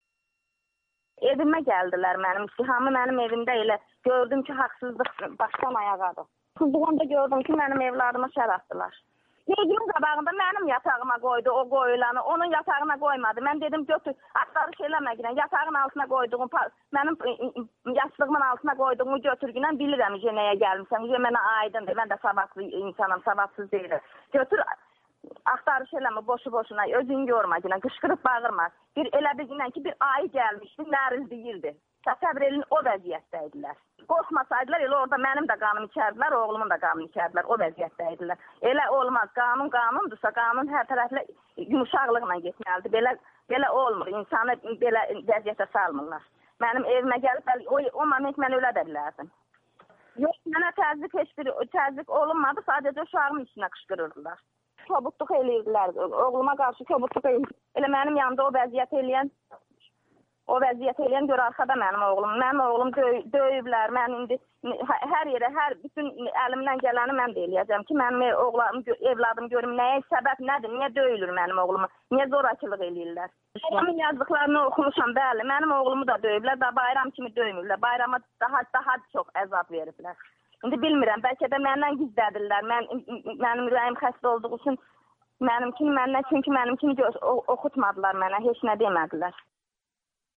Müsahibə videodan sonra davam edir
Həyəcanlı ananı bir az da həyəcanlandırmamaqdan ötrü söhbəti yarıda kəsirik.